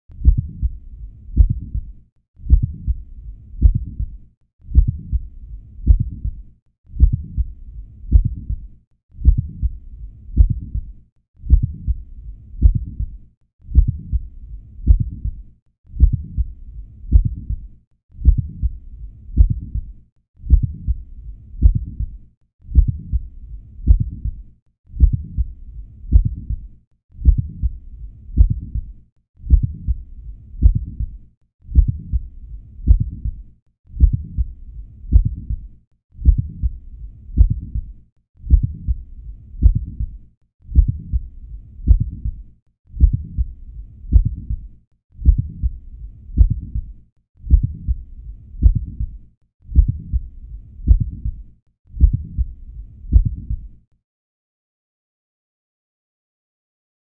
Midsystolic Click